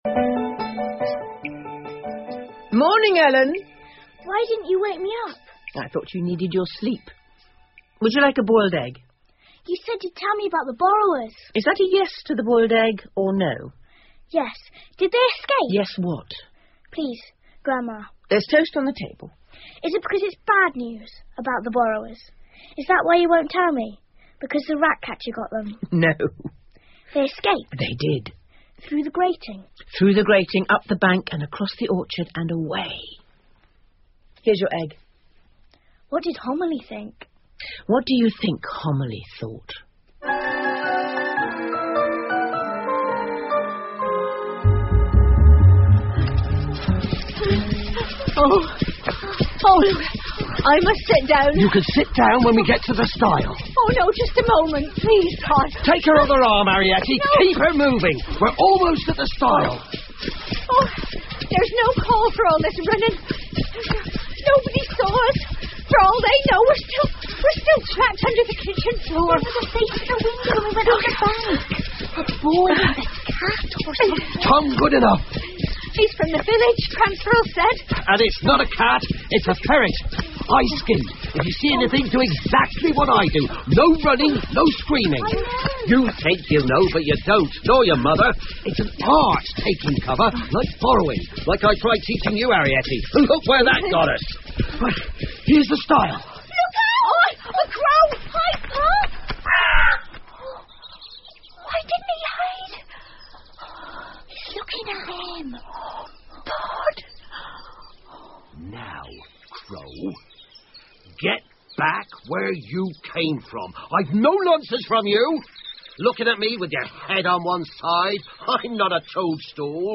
借东西的小人 The Borrowers 儿童广播剧 10 听力文件下载—在线英语听力室